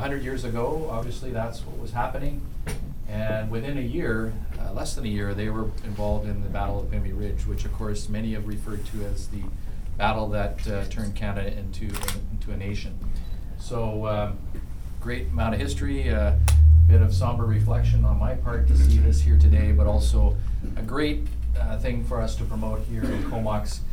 Comox Mayor Paul Ives marks the opening of the 102nd Battalion exhibit at the Comox Museum (March 2016)